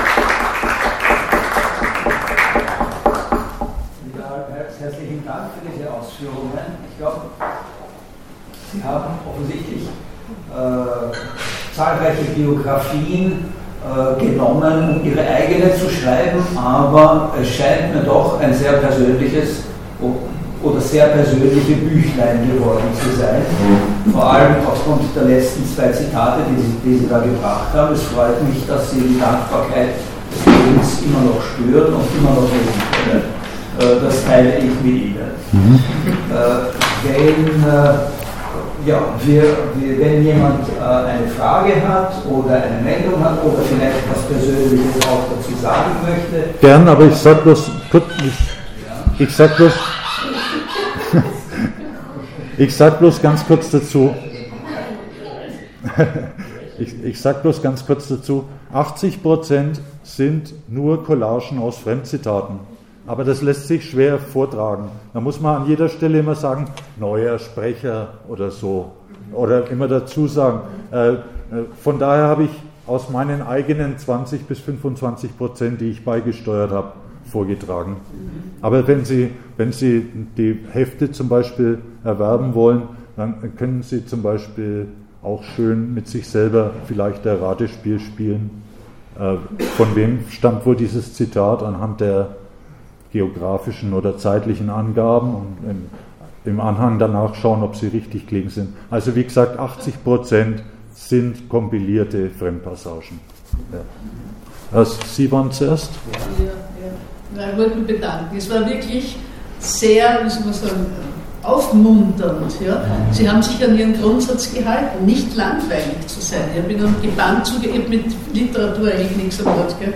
Diskussion_KLD.mp3